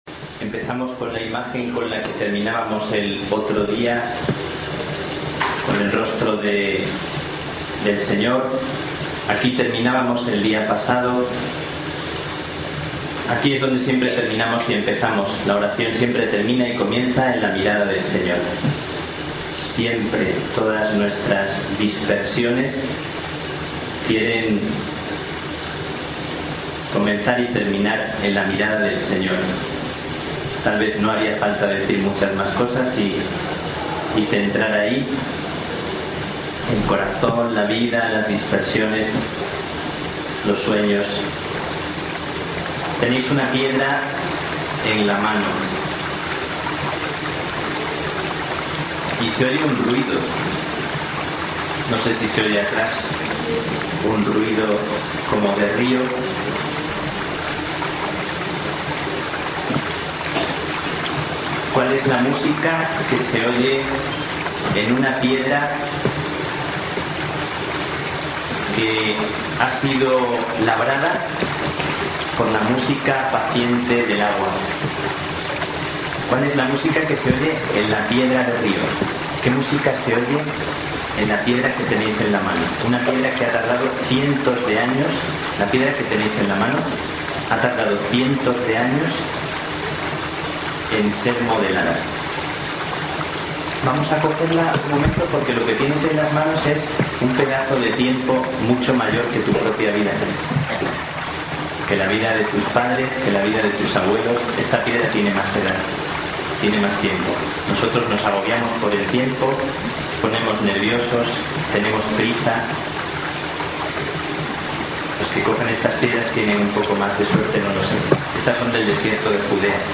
Audio Taller de Oración – 2ª Sesión